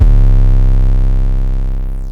YM 808 10.wav